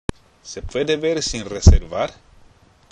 （セプエデ　ベール　シン　レセルバール？）